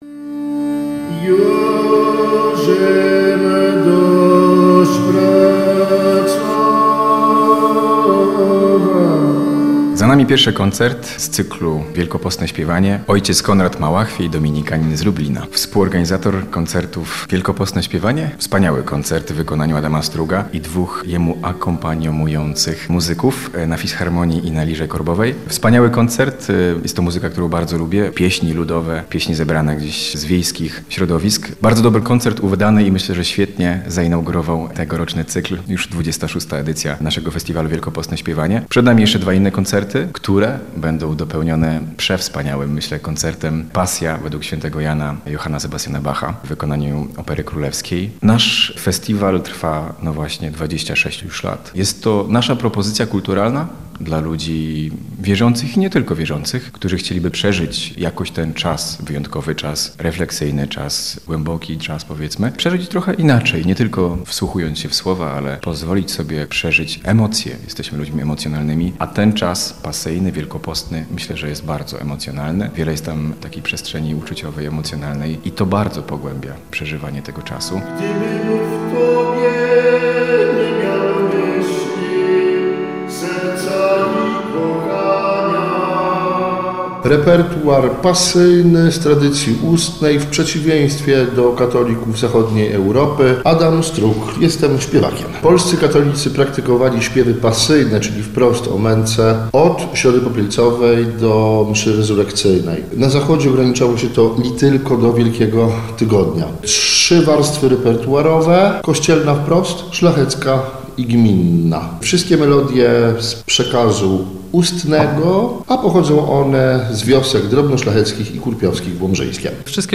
28 lutego rozpoczęła się 26. edycja cyklu koncertów Wielkopostne Śpiewanie w bazylice ojców Dominikanów w Lublinie.
Podczas pierwszego koncertu usłyszeliśmy pieśni pasyjne „Twarde łoże” w wykonaniu Adama Struga – śpiewaka, instrumentalisty, autora piosenek: